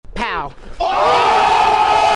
POW.mp3